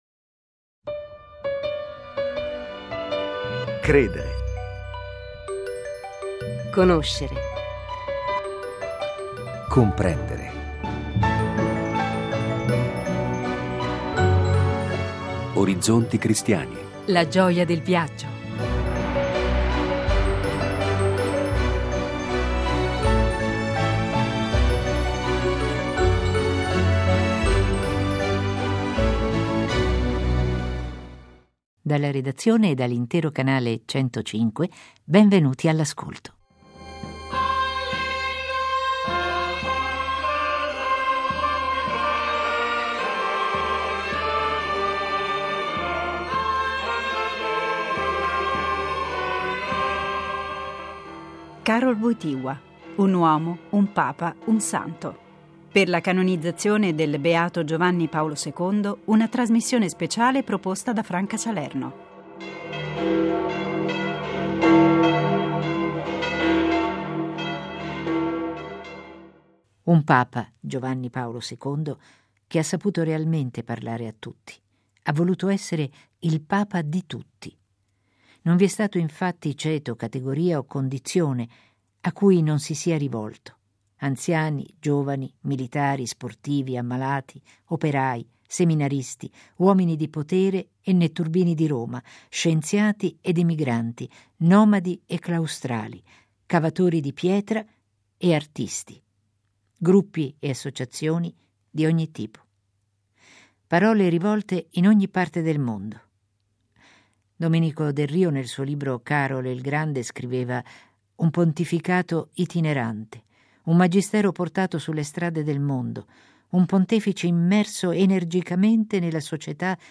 sabato 14 dicembre Continua la panoramica su alcuni insegnamenti del Papa “venuto da lontano”: Giovanni Paolo II, “Karol, il Grande”, del quale riascolteremo l’indimenticabile voce, dal 1978 al 2003, anniversario del suo 25mo anno di Pontificato.